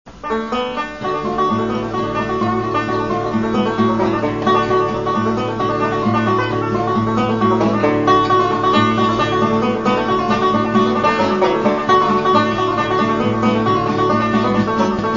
Banjoista bluegrass convinto e ortodosso
banjo.mp3